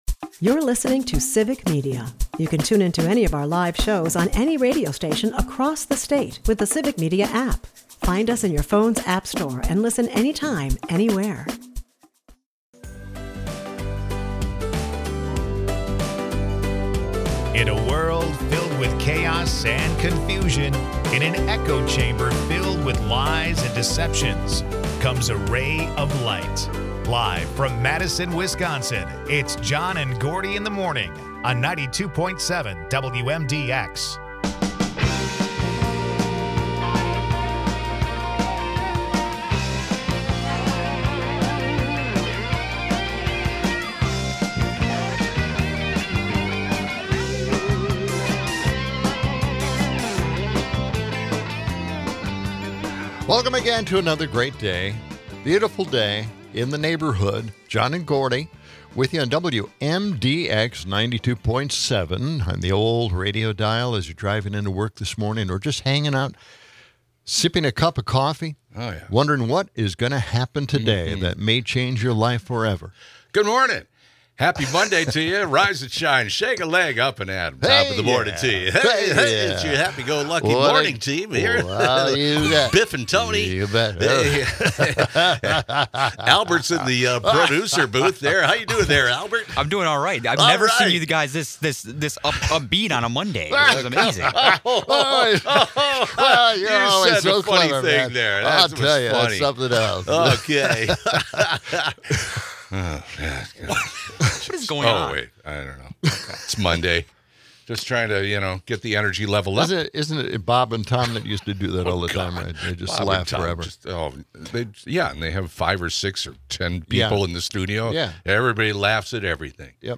Mix in some Would You Rather antics, a splash of weather, and a dash of sports updates and you got yourself a radio show.